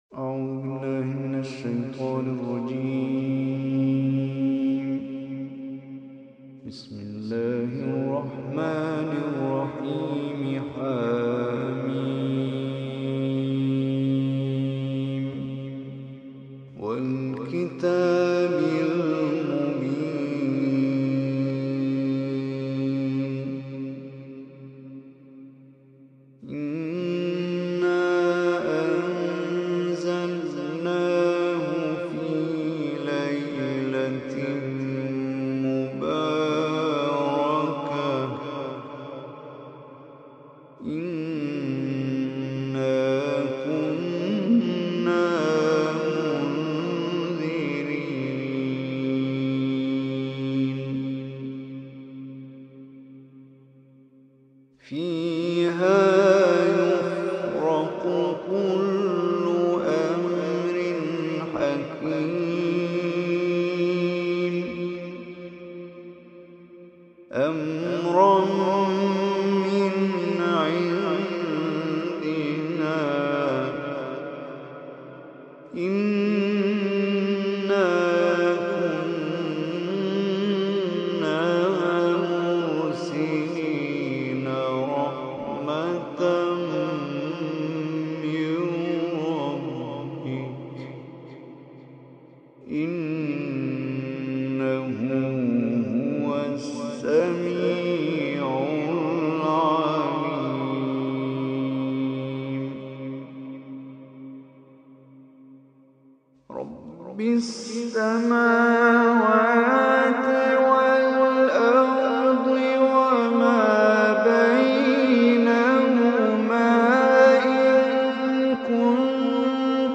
Surah Ad-Dukhan Recitation by Omar Hisham Arabi
Surah Ad-Dukhan, listen or play online mp3 tilawat / recitation in the beautiful voice of Omar Hisham Al Arabi.
044-surah-ad-dukhan.mp3